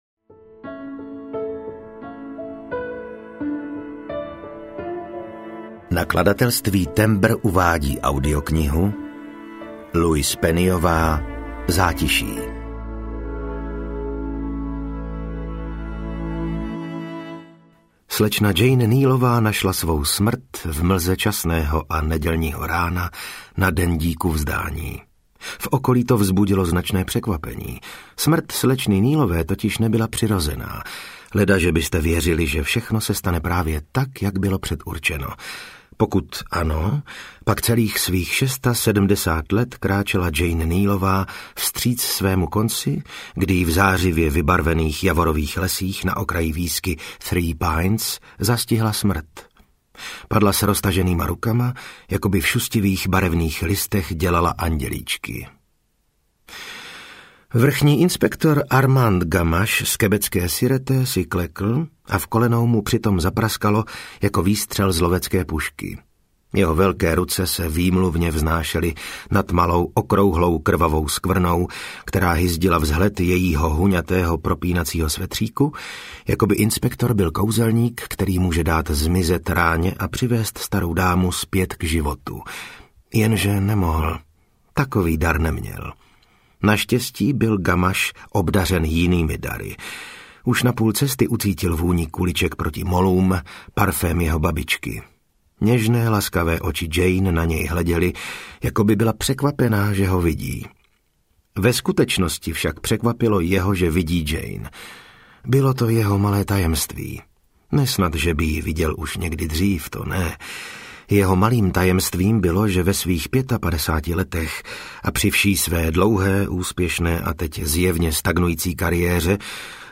Zátiší audiokniha
Ukázka z knihy